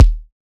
99Sounds x Monosounds - Kick - 005 - D#.wav